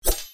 Sword_01.ogg